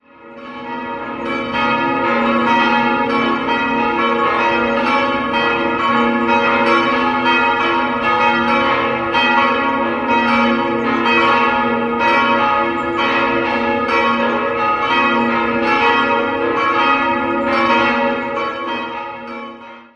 4-stimmiges Geläute: d'-as'-b'-des'' Die Glocken 4 und 3 wurden in den Jahren 1930 und 1965 von der Gießerei Petit&Edelbrock gegossen, Glocke 2 stammt von Michael Dormann aus Elbing (Ostpreußen) aus dem Jahr 1648 und die große Glocke mit ihrem höchst eigentümlichen Klang wurde 1412 von Joiris in Metz gegossen.